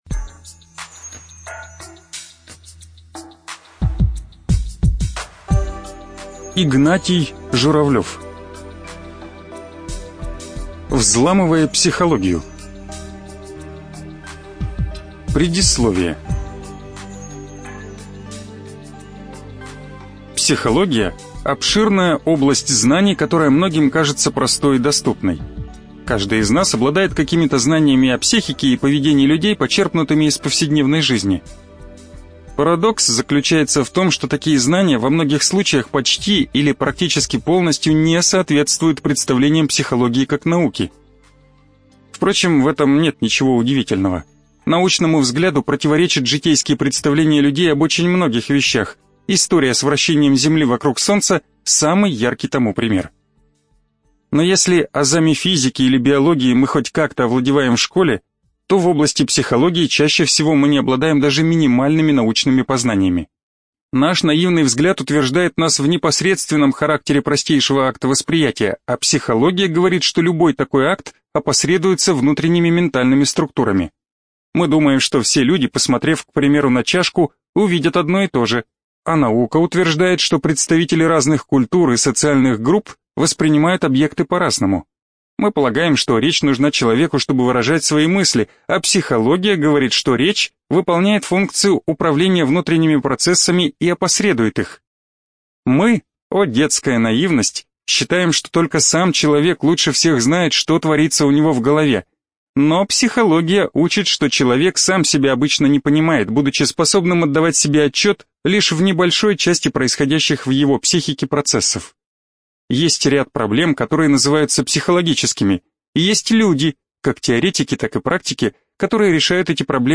Эта аудиокнига поможет разобраться с тем, что происходит в головах людей.